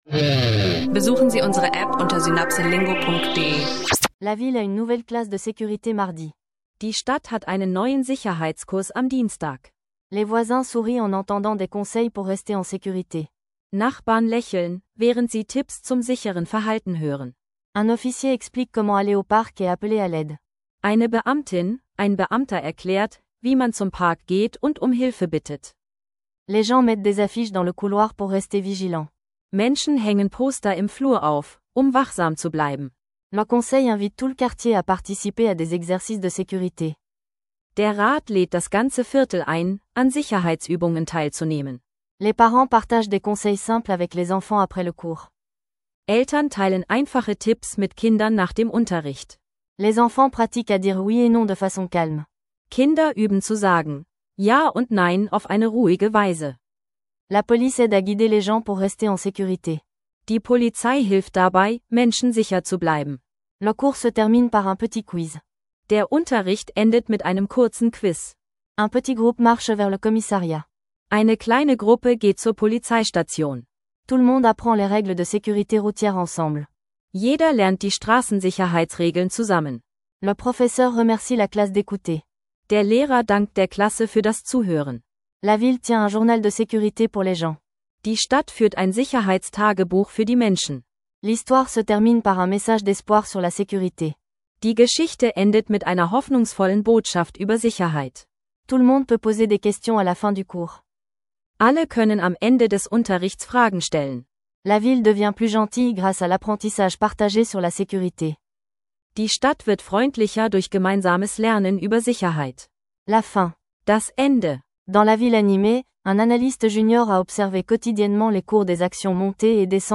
einfache Dialoge zu Sicherheit, Wirtschaft und kultureller Vielfalt